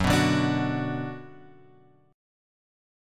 F#sus4#5 chord {2 2 0 x 3 2} chord